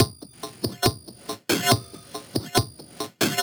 Gamer World Drum Loop 5.wav